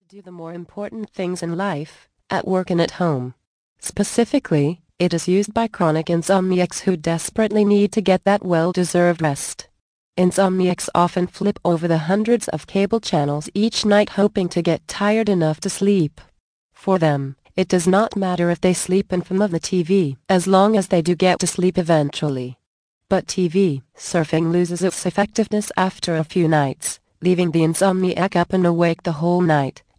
The Magic of Sleep audio book Vol. 1 of 14, 70 min.